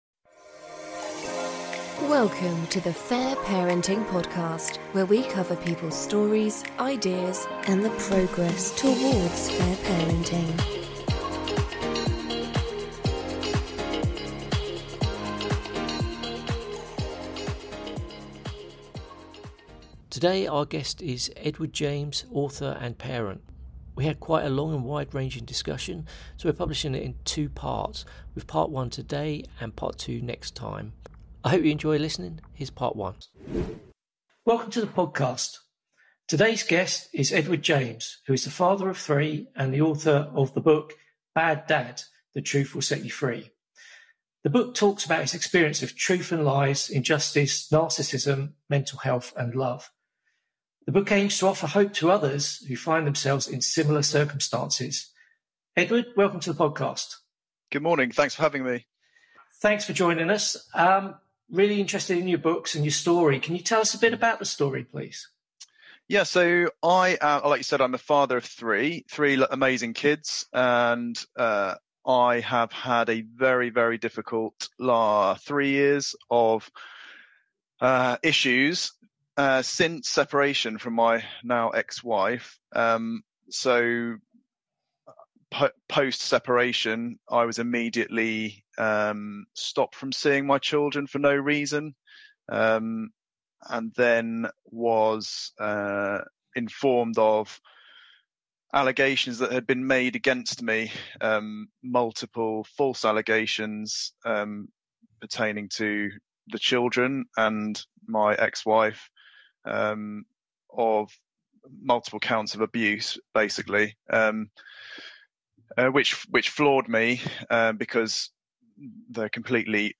We speak on many topics and it's a long conversation so we are publishing it in 2 parts with part 2 in the next episode.